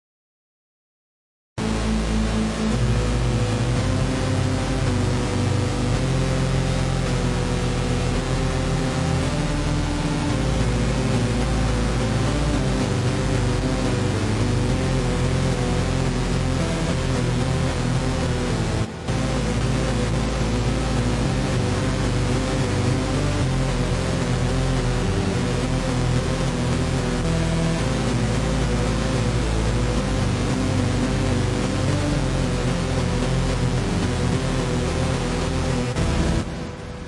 史诗般的钩子合成器
描述：128 BPM EDM合成器
Tag: 128-BPM 合成器 疏水阀 dubstep的 TECHNO 精神恍惚 EDM 反弹 下跌 房子 的dubstep 毛刺跳 俱乐部